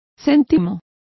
Complete with pronunciation of the translation of cent.